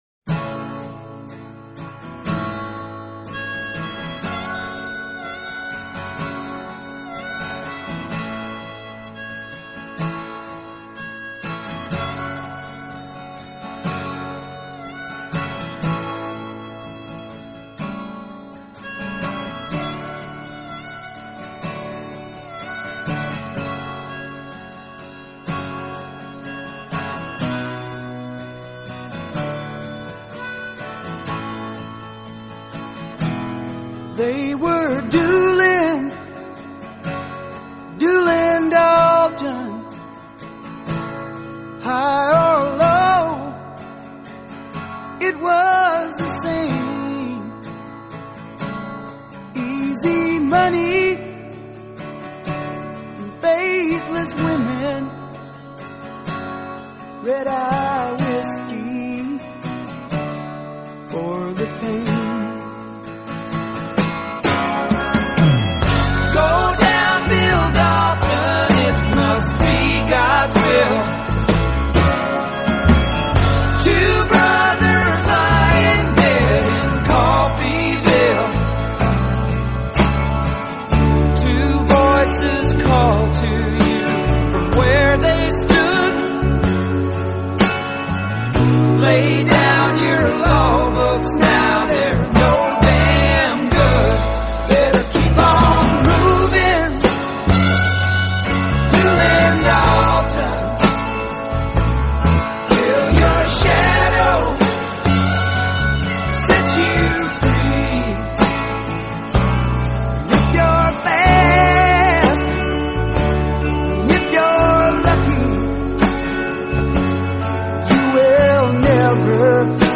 Country Rock, Soft Rock